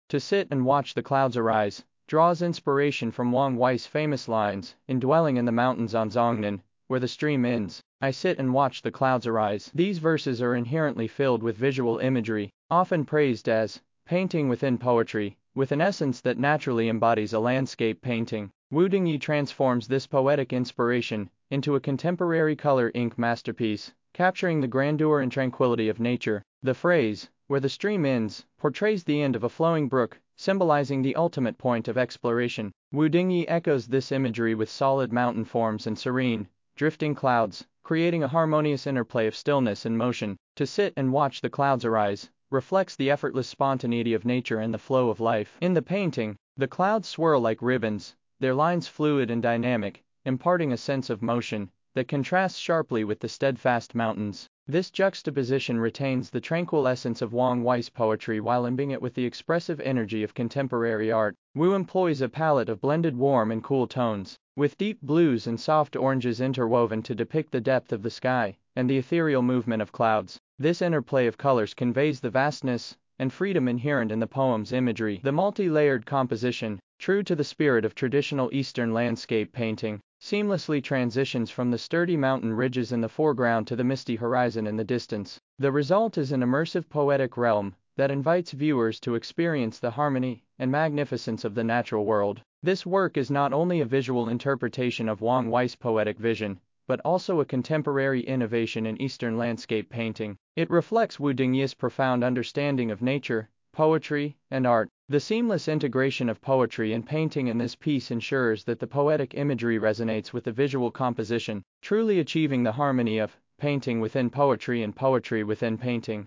英文語音導覽